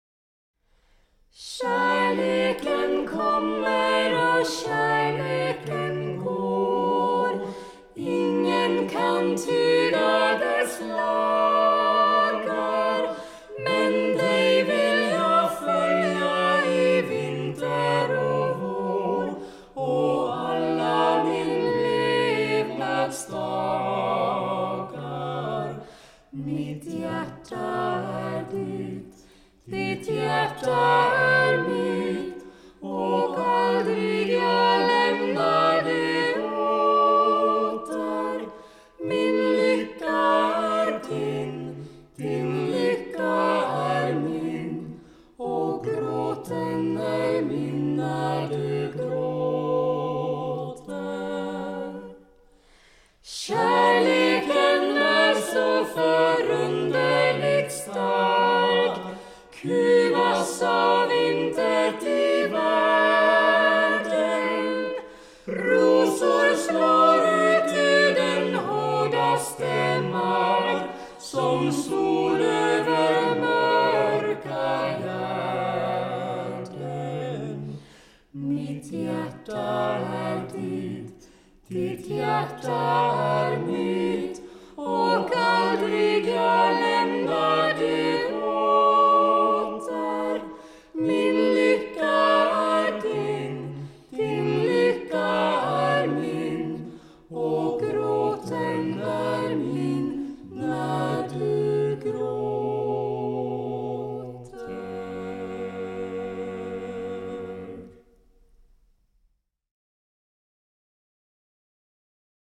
sång